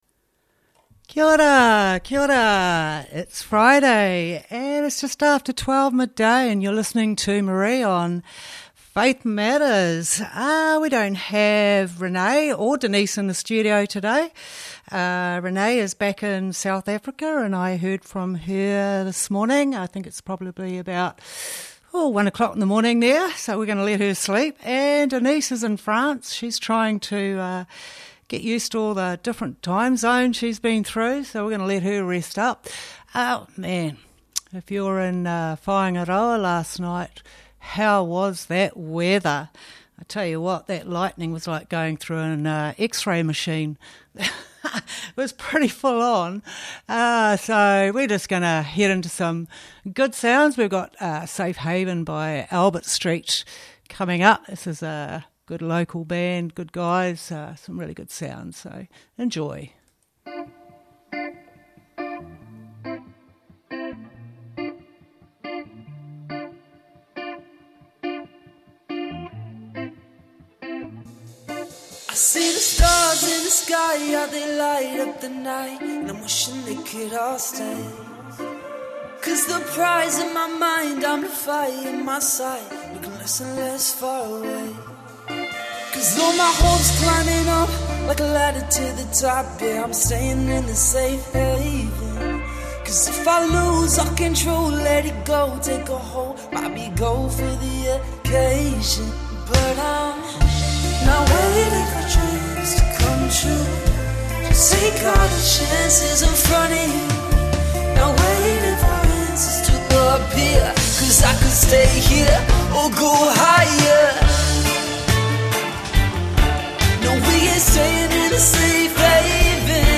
Coincidence..By Chance... Or...Is It A Miracle? Tune into some Good Sounds and Live Convo into the unexpected ...